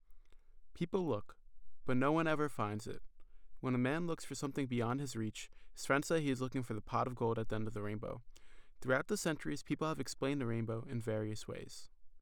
p016_rainbow_03_regular.wav